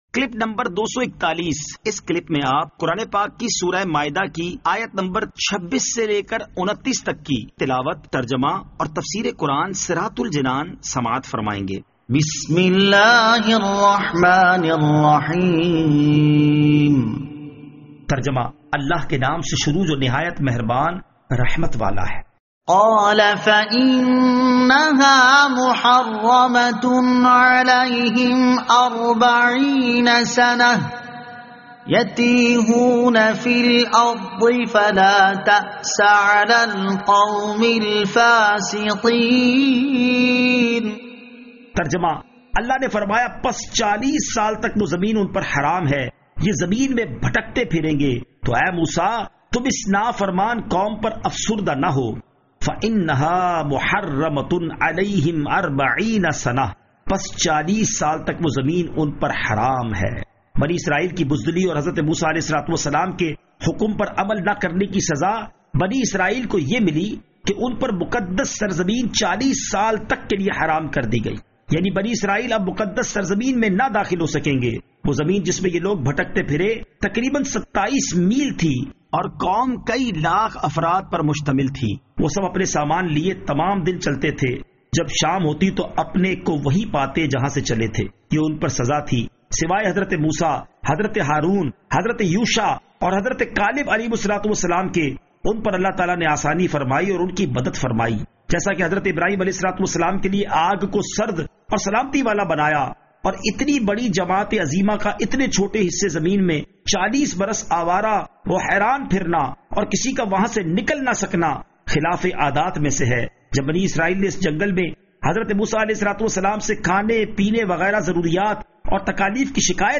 Surah Al-Maidah Ayat 26 To 29 Tilawat , Tarjama , Tafseer